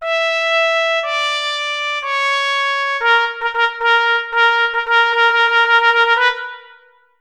Unison Rhythm, mm, 197-200 (MIDI audio file) (w/click)           Unison Rhythm, mm. 273-276 (MIDI audio file) (w/click)